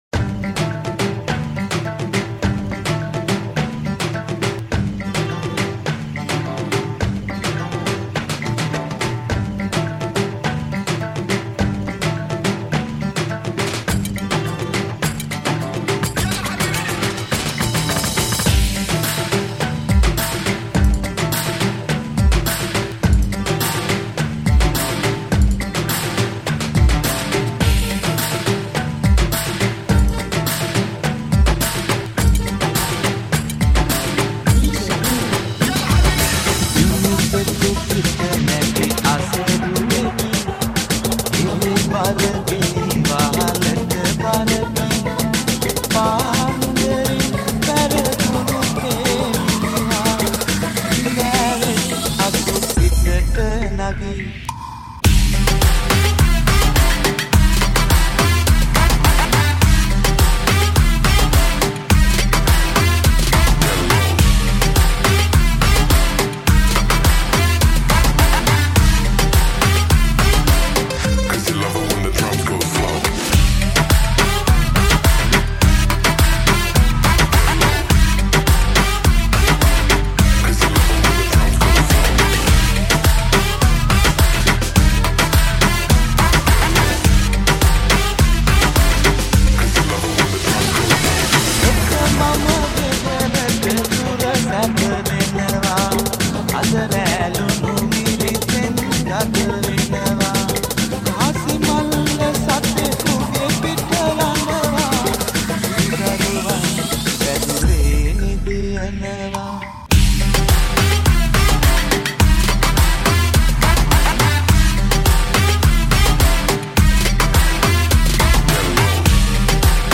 Moombahton Remix